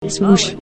swoosh_py4kdky_1FWsTE6.mp3